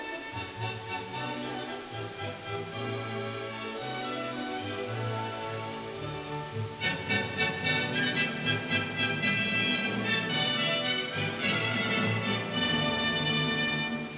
These are arranged in degrading quality.
Yet most 8-bit files sound terrible with lots of static & noise.